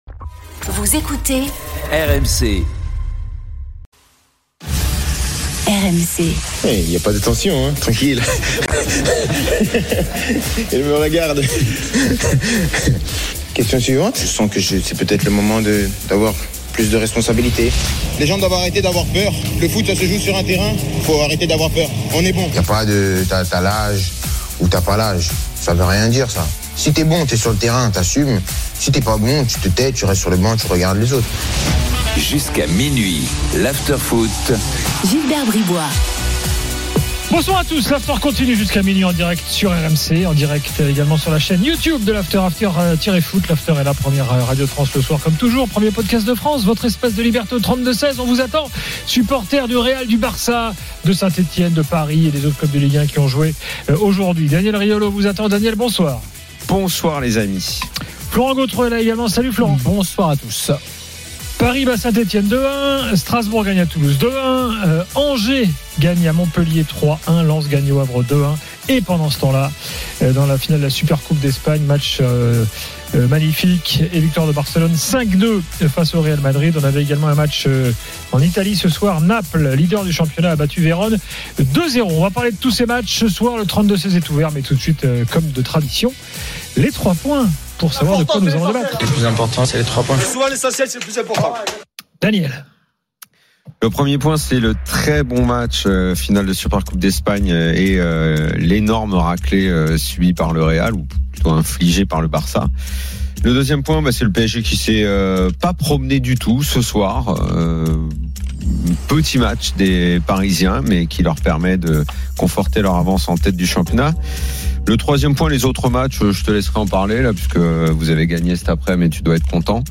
Chaque jour, écoutez le Best-of de l'Afterfoot, sur RMC la radio du Sport ! L’After foot, c’est LE show d’après-match et surtout la référence des fans de football depuis 19 ans !